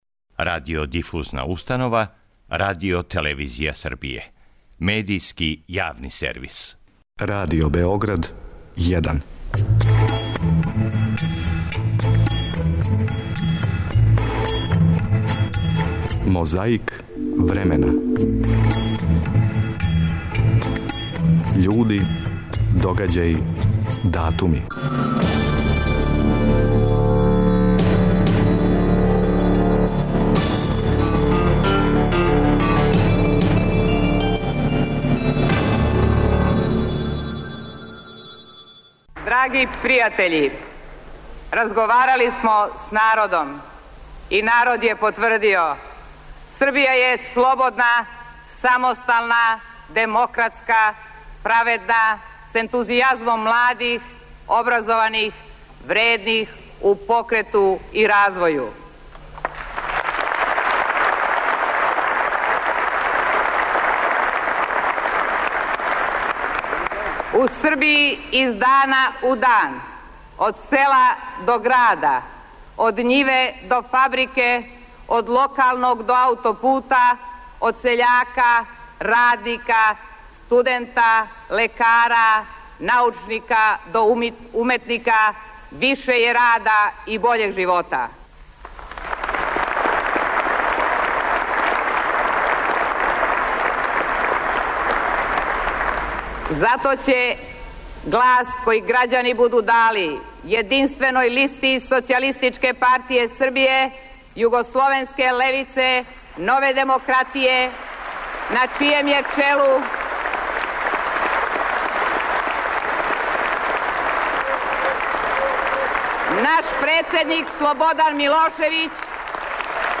Присећамо се речи Горице Гајевић у београдском Сава центру, на изборној конференцији СПС-ЈУЛ-НД, 18. септембра 1997. године.
Подсећа на прошлост (културну, историјску, политичку, спортску и сваку другу) уз помоћ материјала из Тонског архива, Документације и библиотеке Радио Београда. Свака коцкица Мозаика је један датум из прошлости.